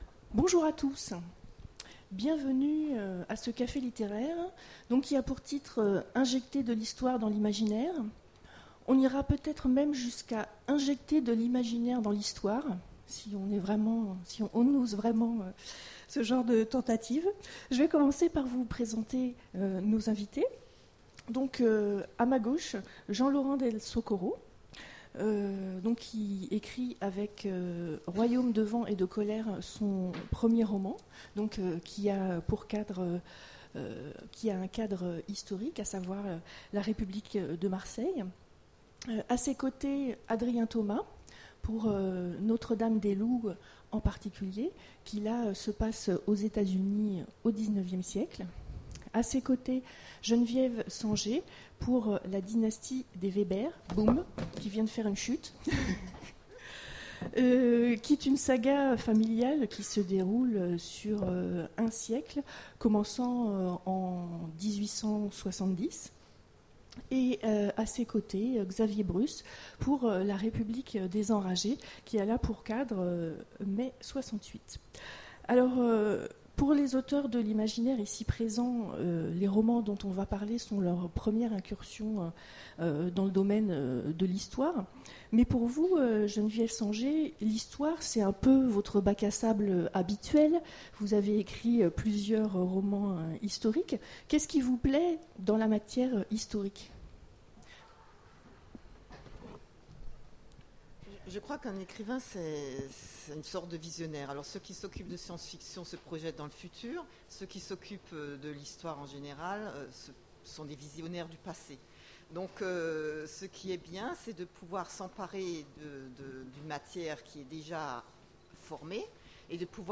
Imaginales 2015 : Conférence Injecter de l'histoire...